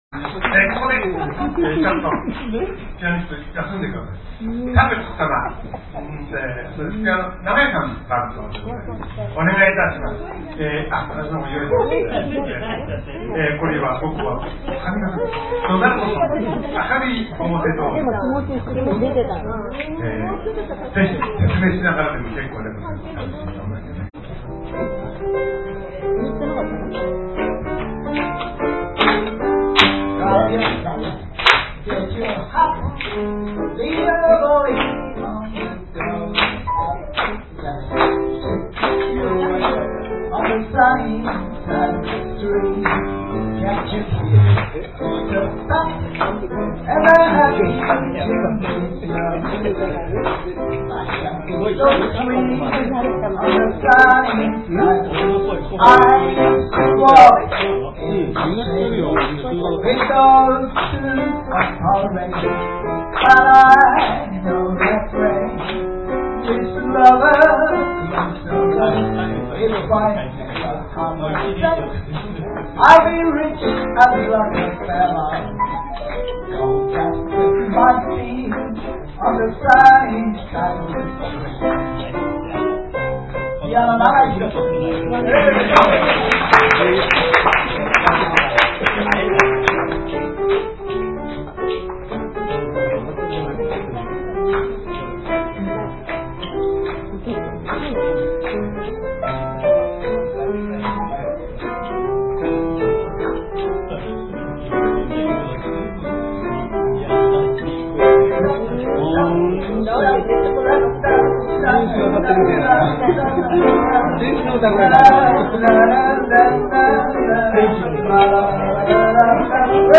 ミュージック・レストラン
「アルテリーベ」   ピアノ伴奏での歌唱 「明るい表通りで」